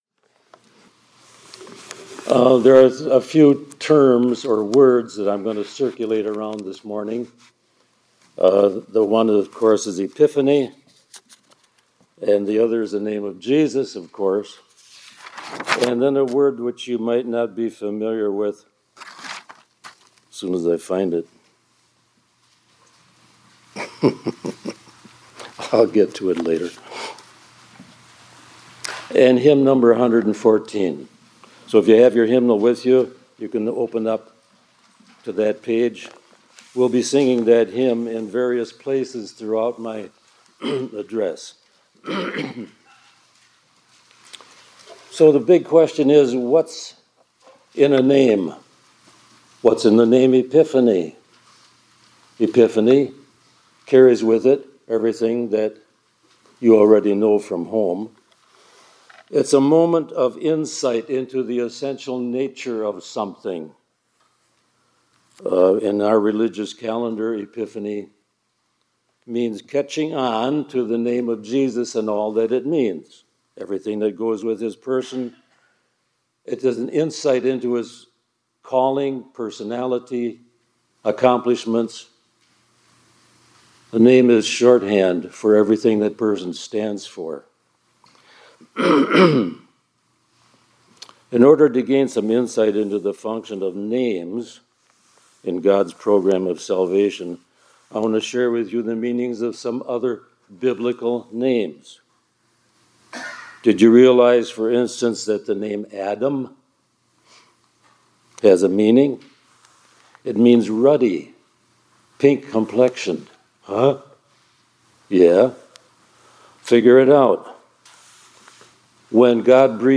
2025-01-13 ILC Chapel — “Jesus” is a Name of Supreme Gravitas